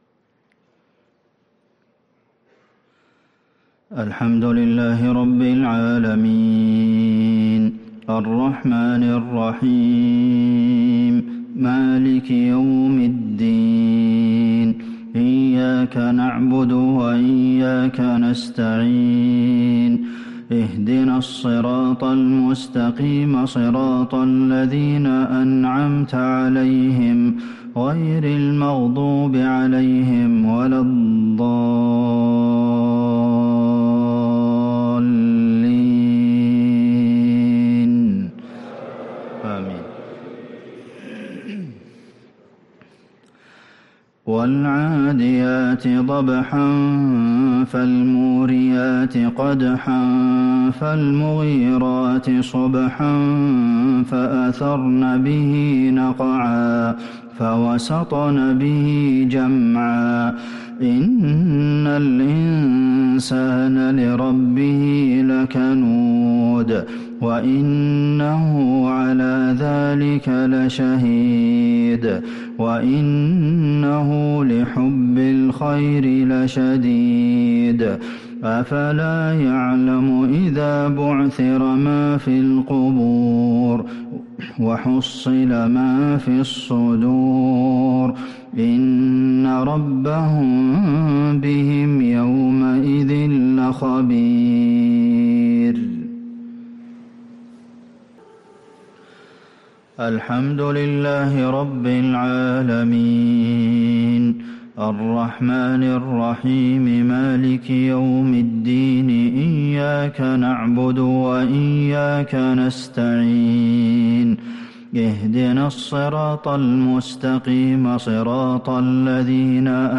مغرب الجمعة 7 محرم 1444هـ سورتي العاديات و المسد | Maghrib prayer from Surat Al-'Adiyat & AlMasd 5-8-2022 > 1444 🕌 > الفروض - تلاوات الحرمين